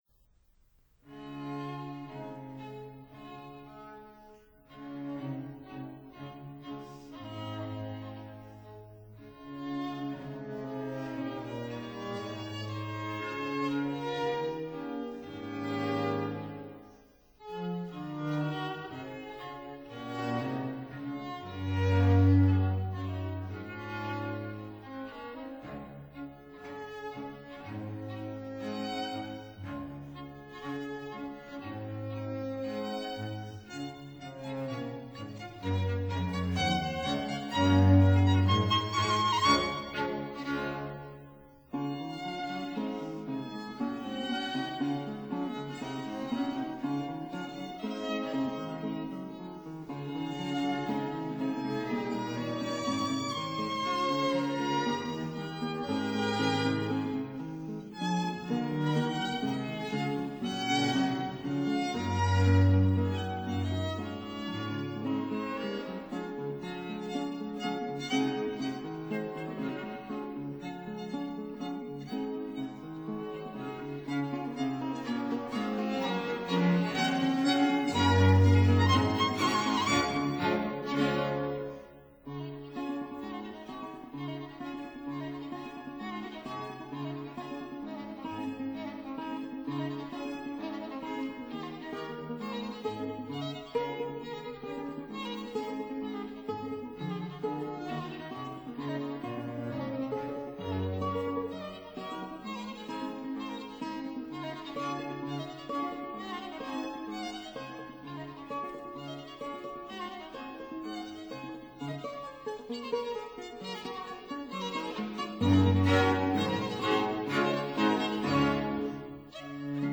(Period Instruments)